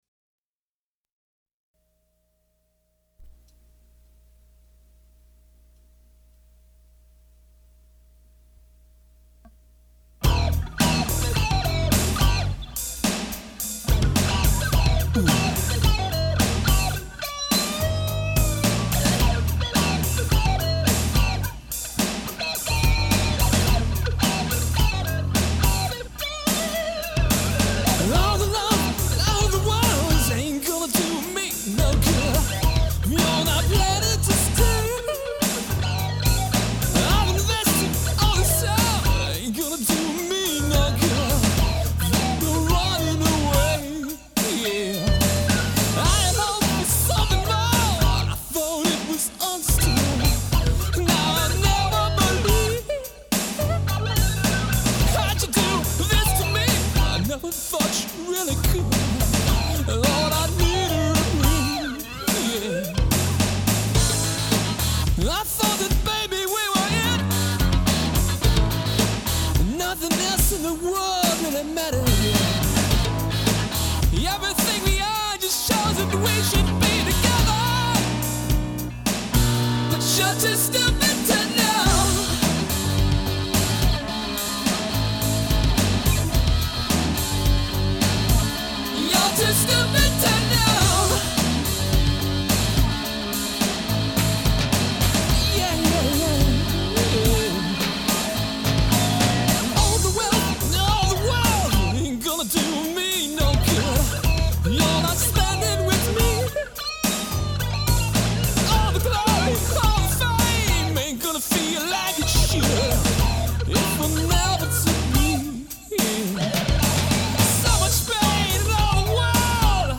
recorded this in 1992 on a half inch 8 track tape in his garage. It is the familiar story of how one person in the relationship can be giving it all up but the other person is too busy or too stupid to know.